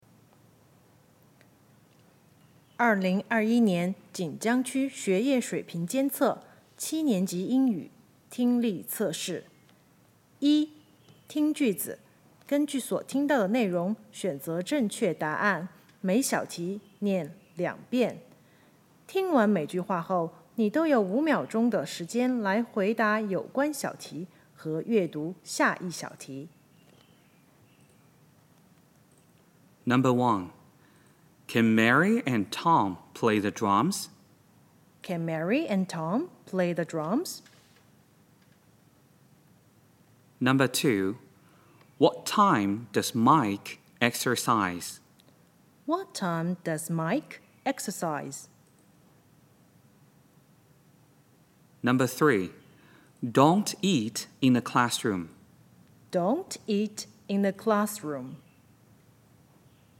第一部分听力测试(共30小题,计30分)
每小题念两遍。